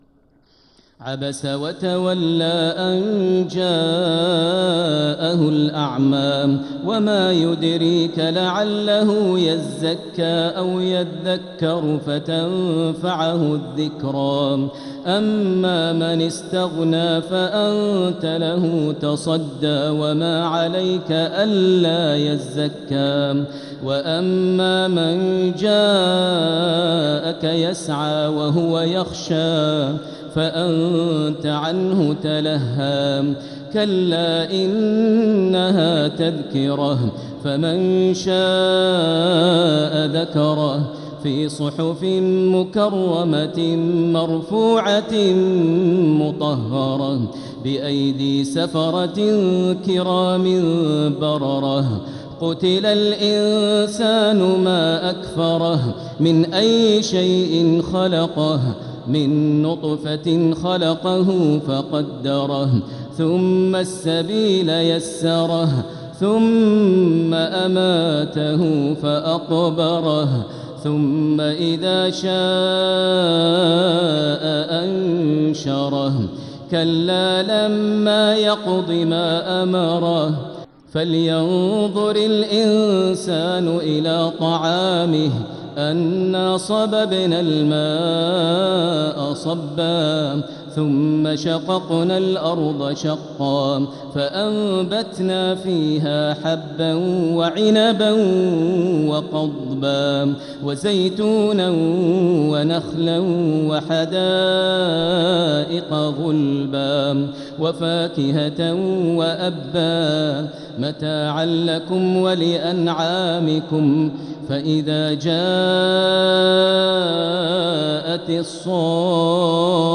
سورة عبس | مصحف تراويح الحرم المكي عام 1446هـ > مصحف تراويح الحرم المكي عام 1446هـ > المصحف - تلاوات الحرمين